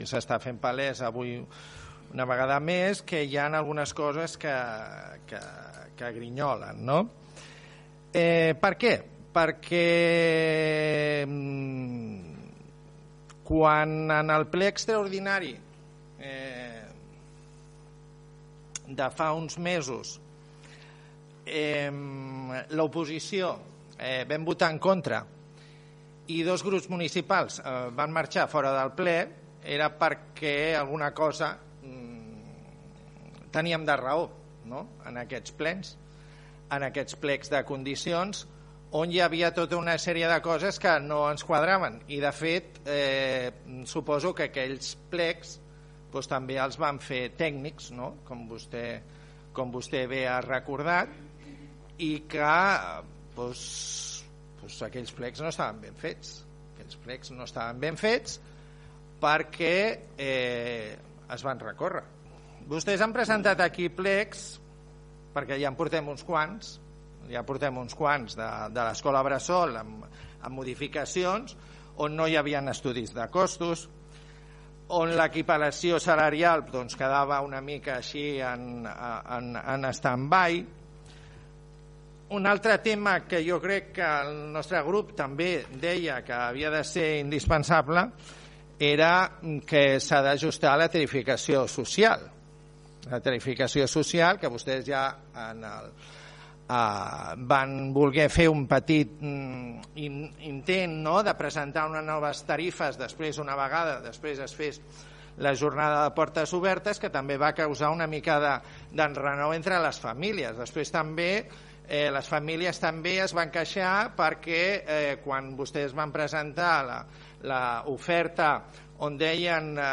El portaveu del PP, Diego Parra, va justificar la seva abstenció perquè els era difícil “donar un vot de confiança” després de l’historial amb els plecs anteriors i la gestió de l’equip de govern en el tema de l’escola bressol: